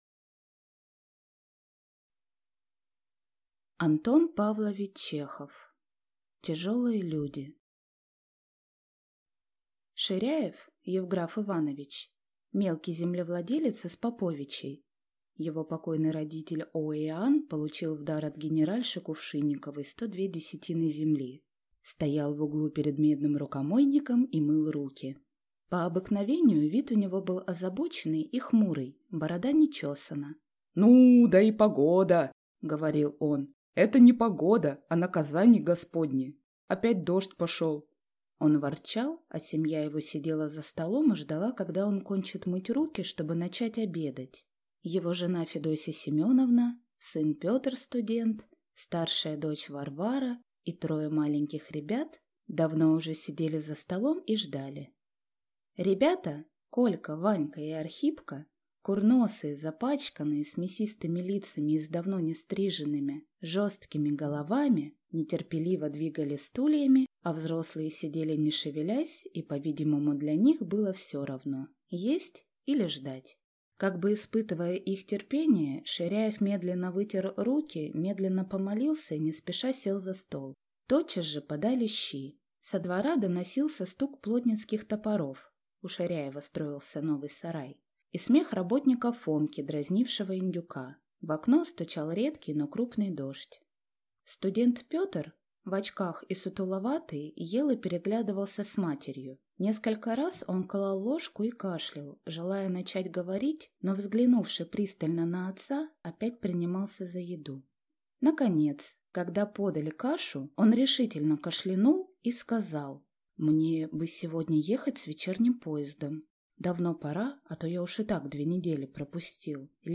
Аудиокнига Тяжелые люди | Библиотека аудиокниг